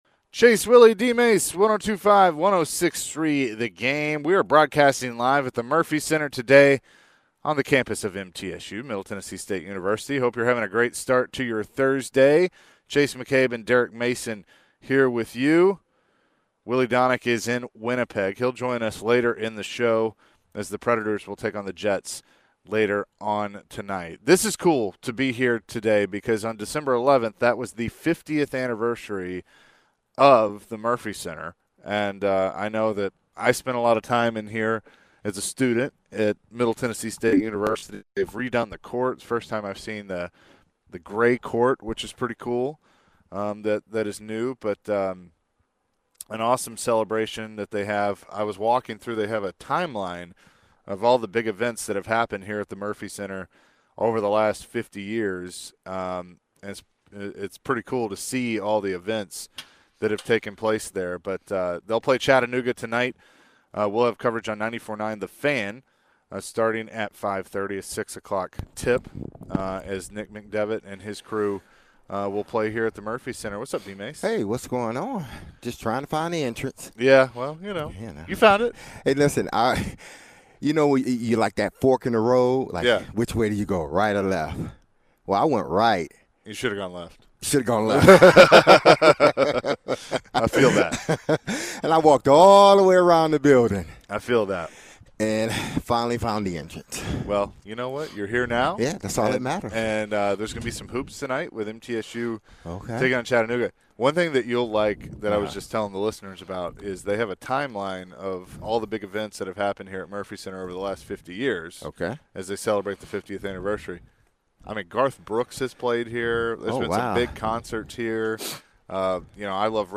Hour 1: LIVE @ MTSU